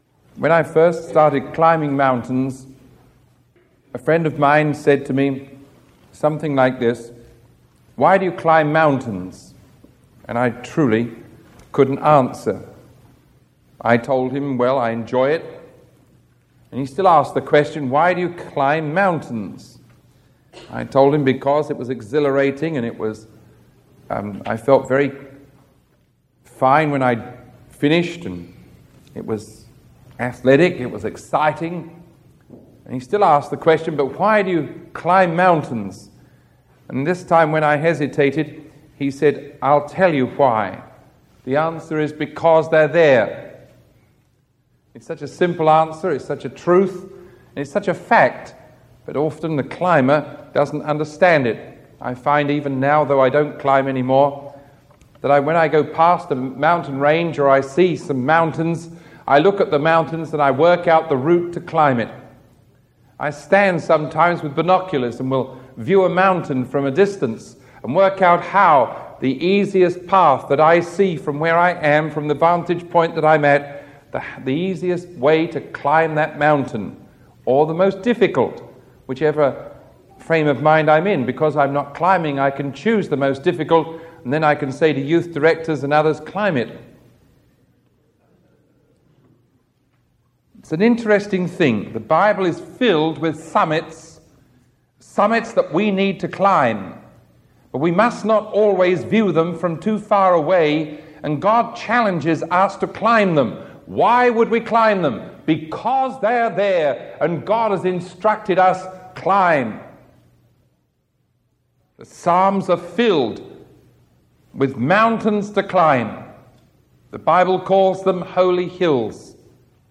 Sermon 0944A recorded on October 21